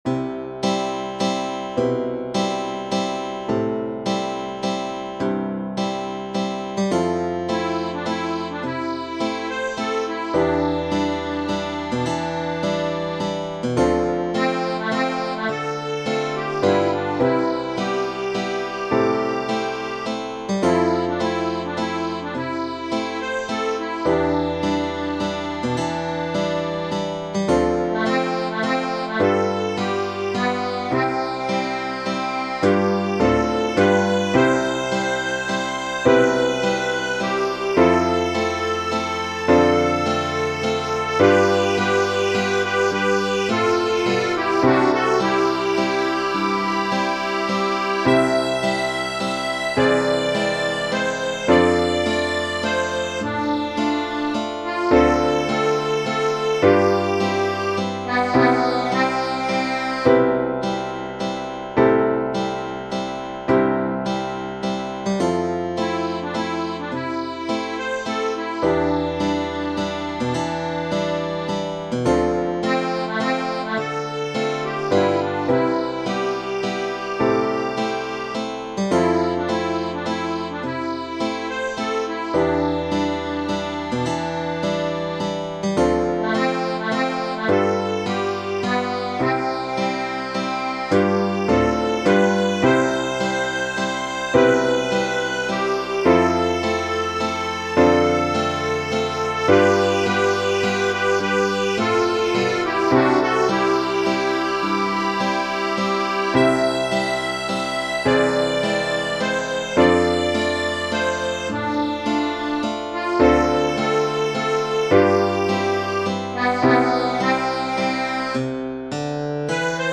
Genere: Ballabili